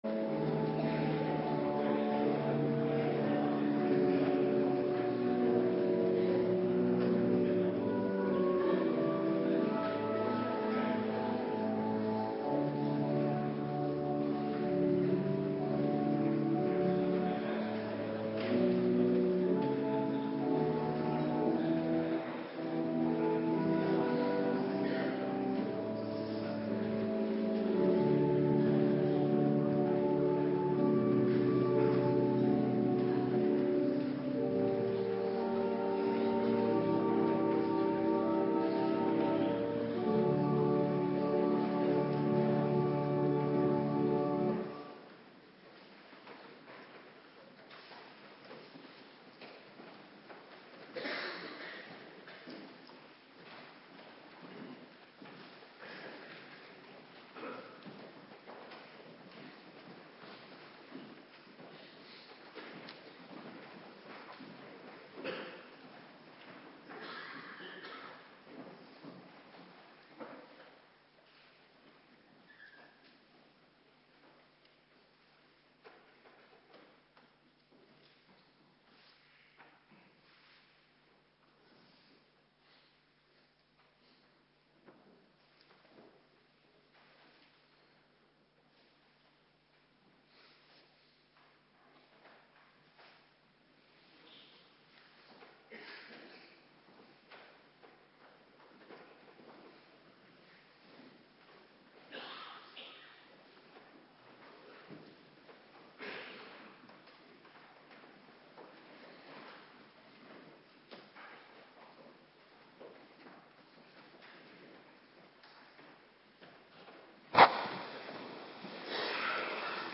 Locatie: Hervormde Gemeente Waarder